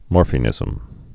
(môrfē-nĭzəm, môrfə-)